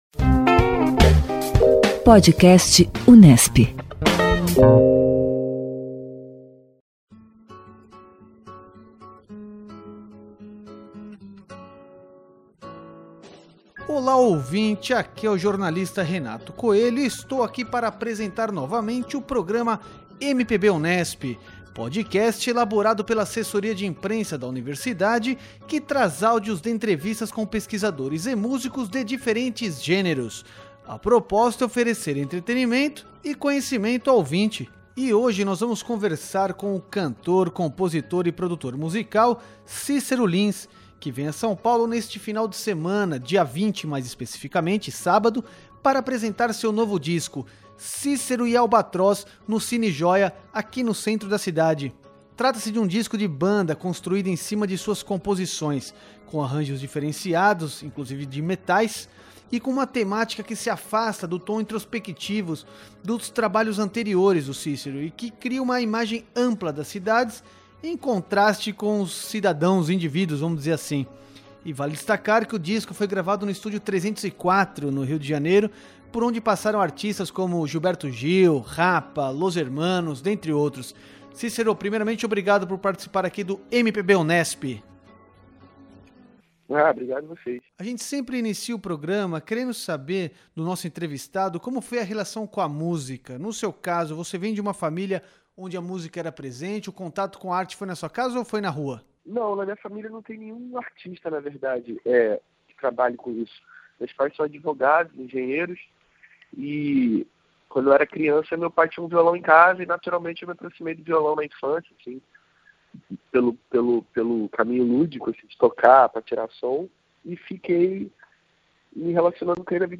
A entrevista conta com a canção "A Cidade".